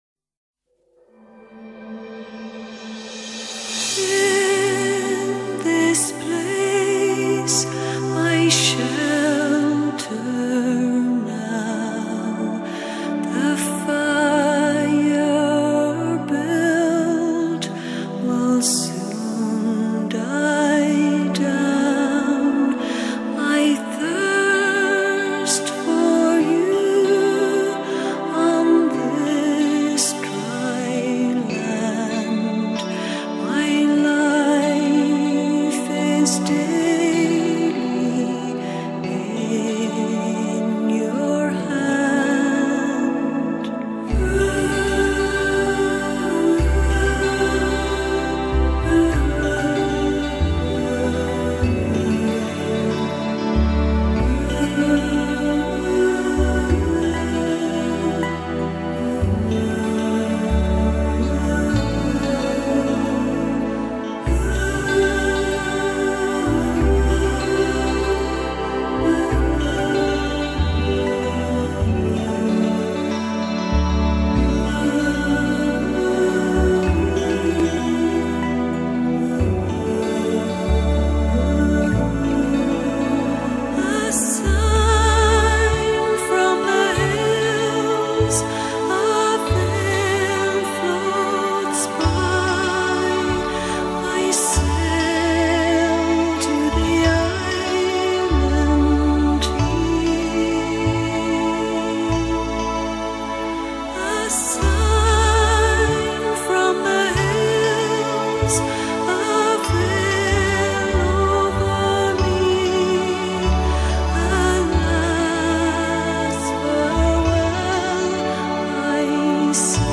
有时候忧伤中却又充满无限的希望，它适 时的捕捉了爱尔兰音乐所独有的哀愁感，这就是爱尔兰音乐的特色。